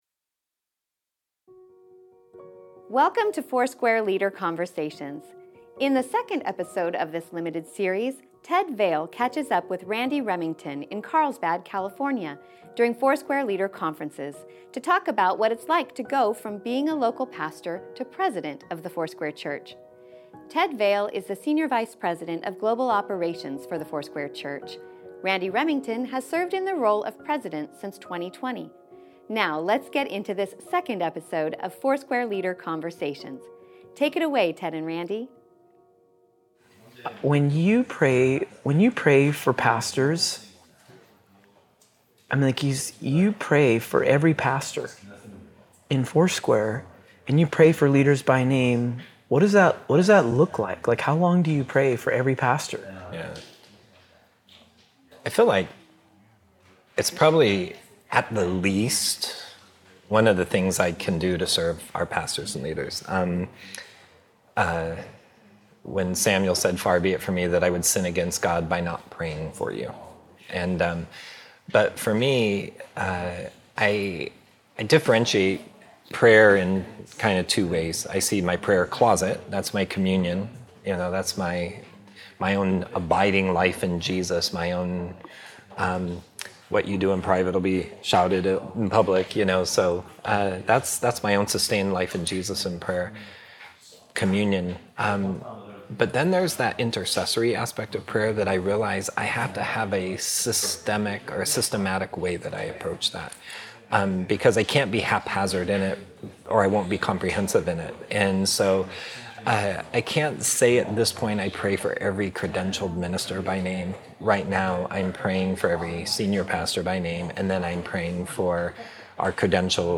in Carlsbad, Calif., during Foursquare Leader Conferences